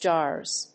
/dʒɑrz(米国英語), dʒɑ:rz(英国英語)/